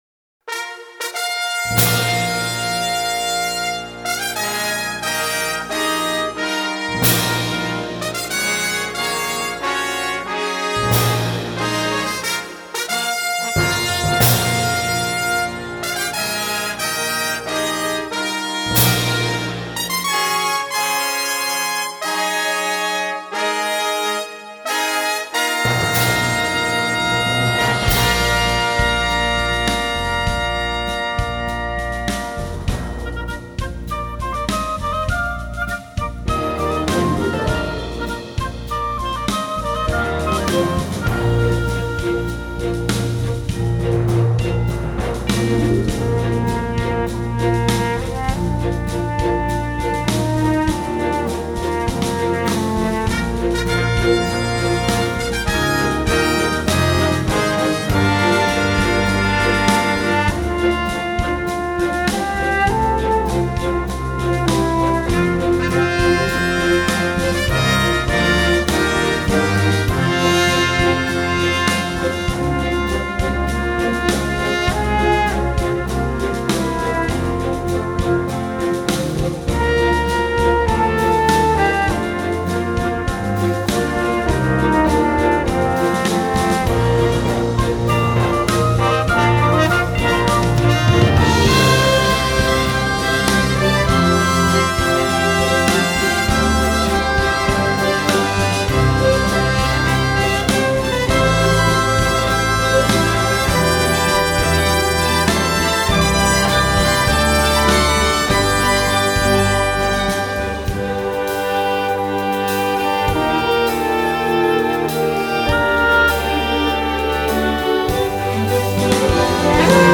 Orchestra Recording